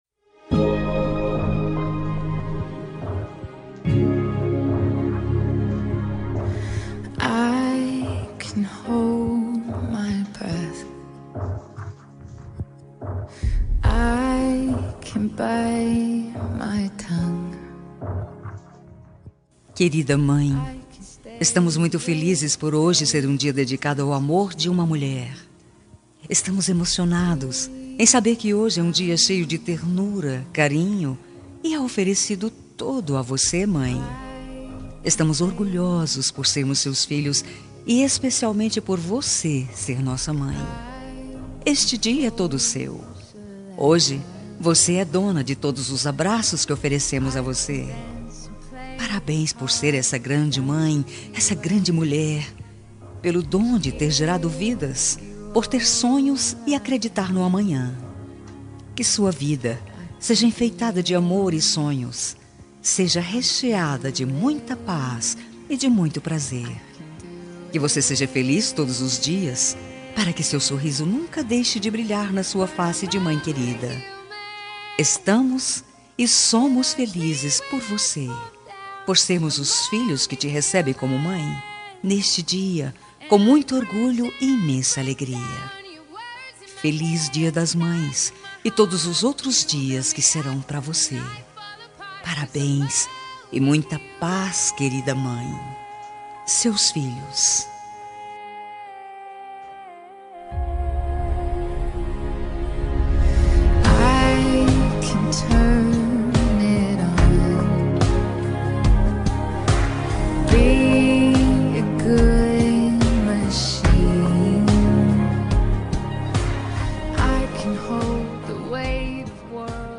Dia das Mães – Para minha Mãe – Voz Feminina – Plural – Cód: 6507